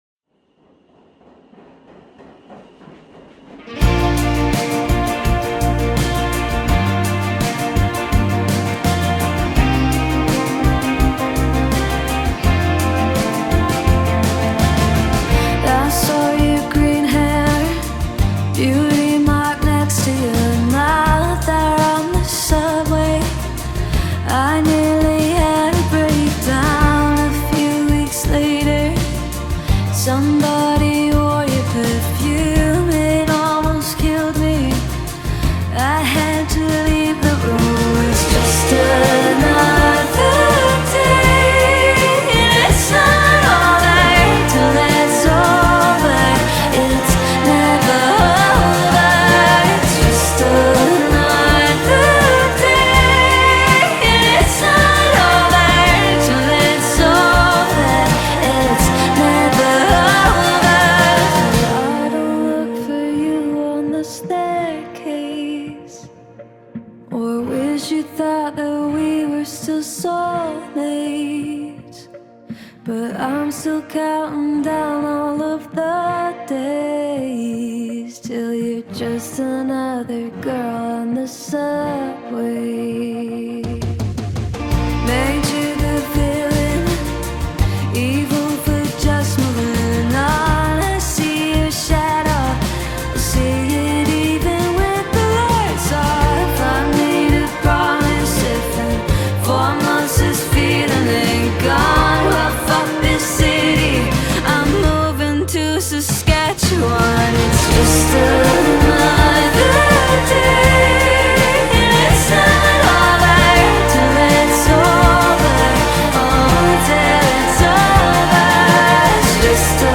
BPM83
Audio QualityPerfect (High Quality)